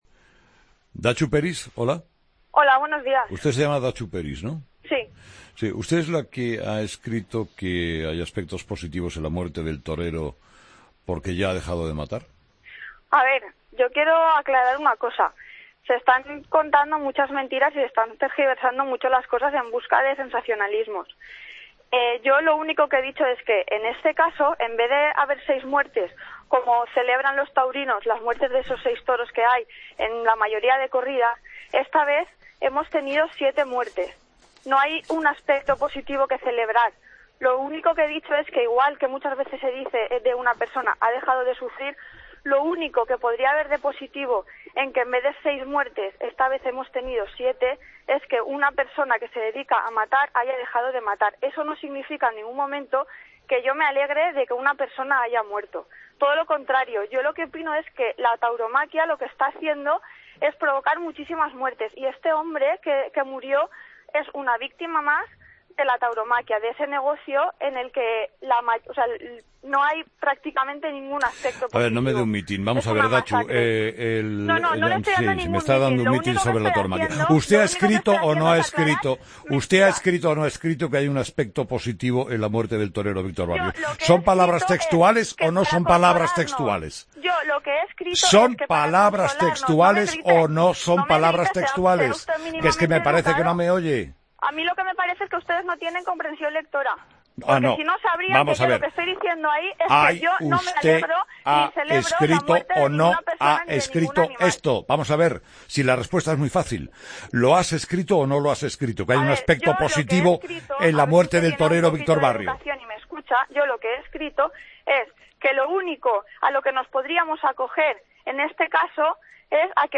Escucha la entrevista a Datxu Peris, concejal de Guanyar Catarroja, en 'Herrera en COPE' en julio de 2016